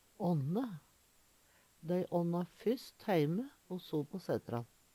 ånne - Numedalsmål (en-US)